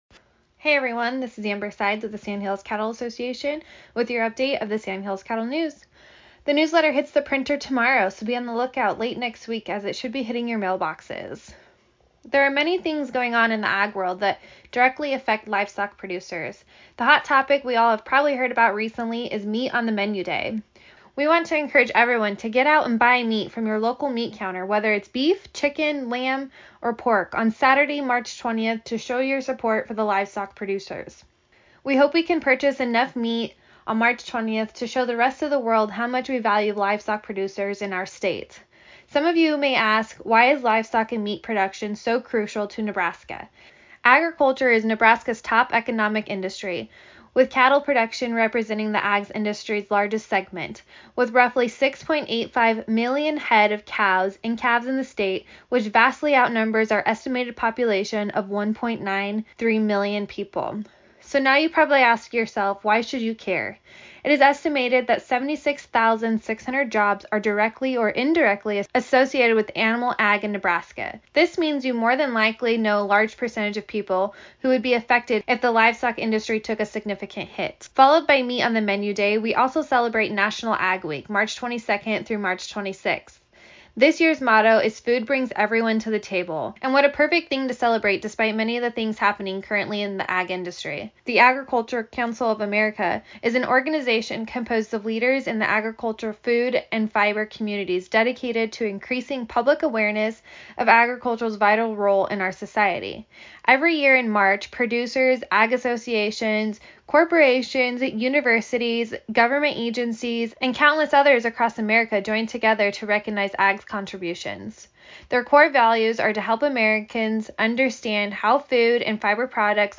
Radio spot for March 18, 2021